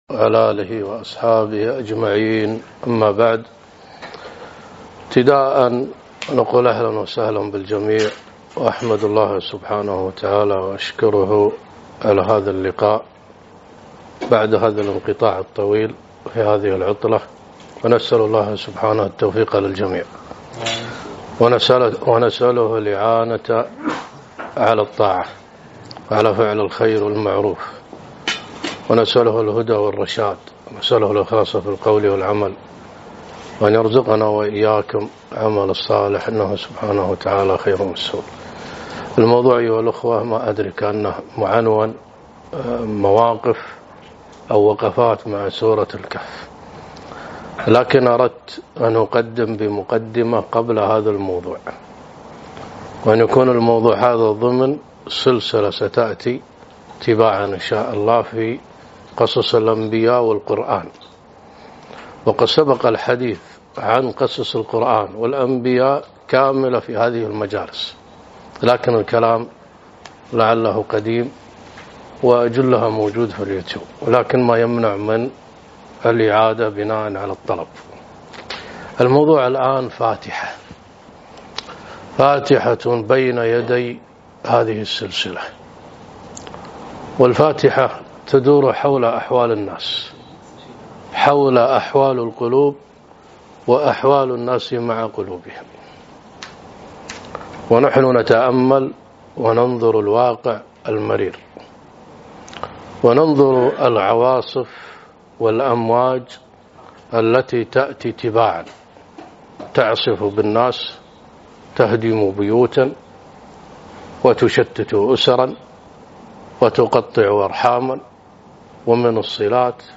محاضرة - تفقد قلبك وإيمانك